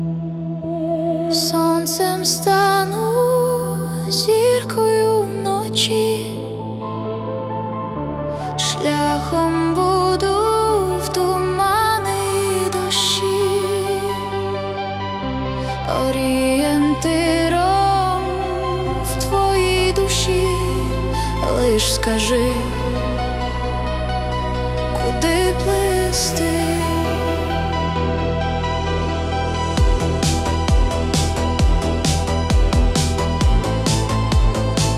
Pop Vocal